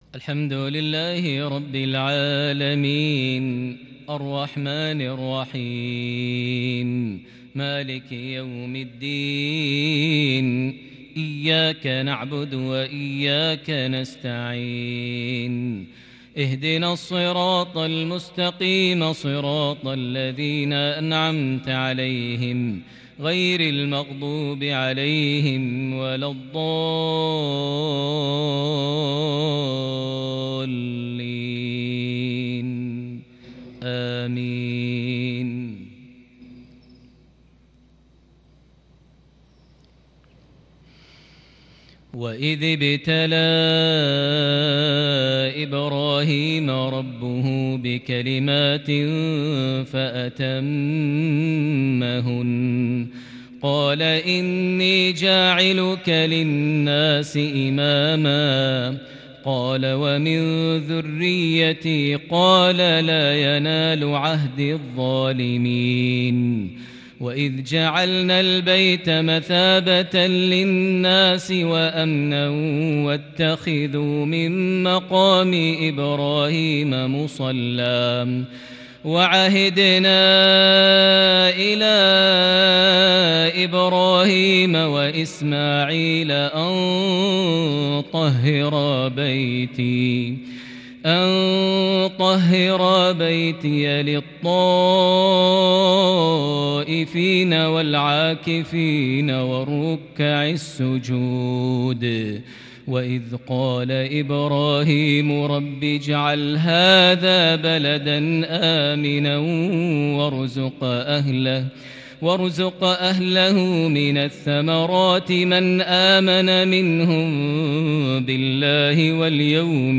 عشائية فريدة بالكرد من سورة البقرة (124-134) | الأربعاء 20 صفر 1442هـ > 1442 هـ > الفروض - تلاوات ماهر المعيقلي